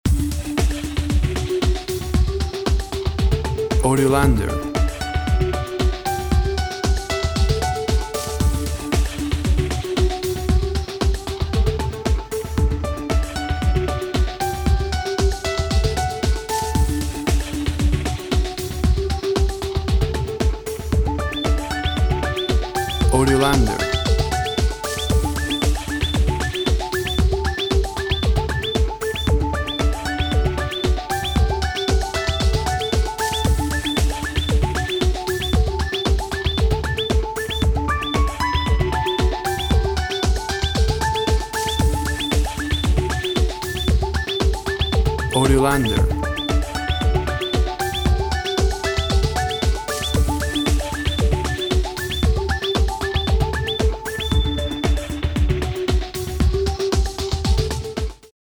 Tempo (BPM) 115